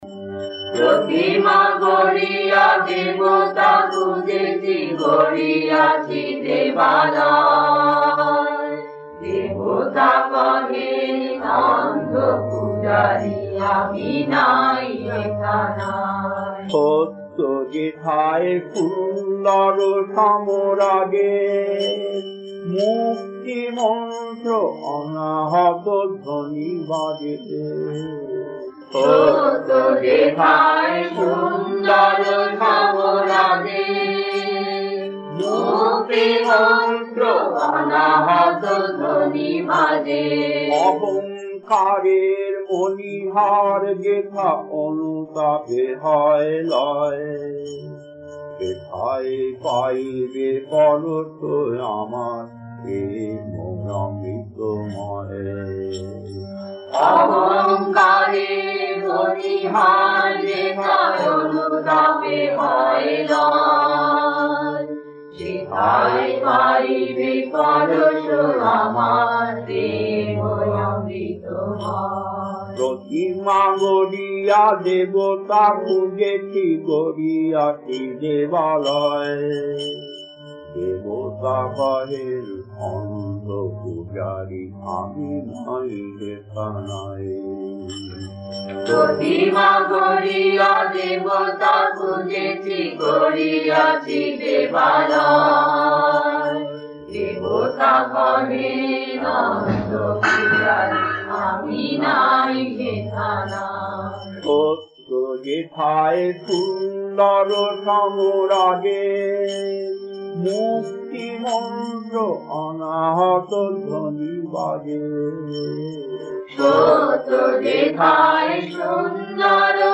Kirtan B2-1 Chennai mid 1980�s, 62 minutes 1.